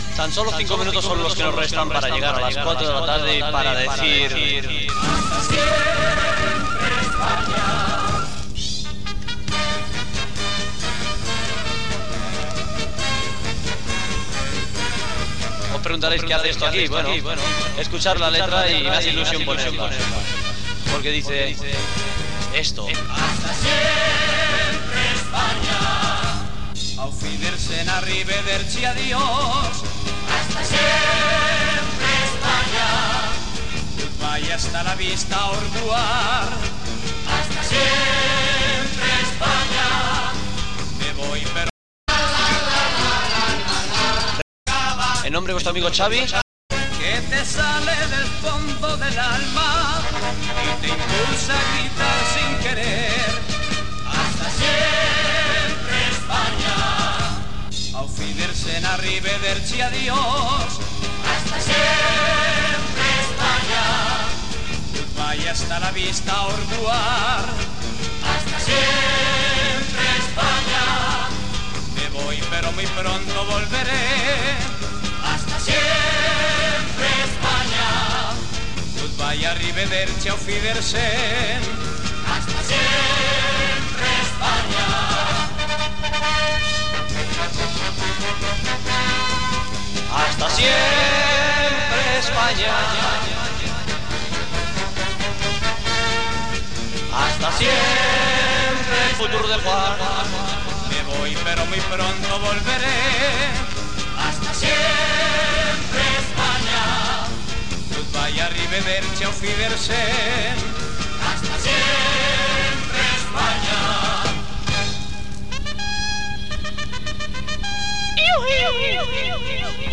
Hora i comiat del programa amb la careta de sortida del programa
Musical